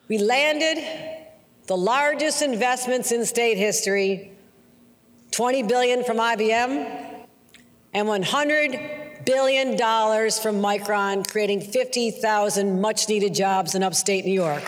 During her State of the State Address, Governor Kathy Hochul reiterated the importance of the Micron Facility to be built in the Town of Clay.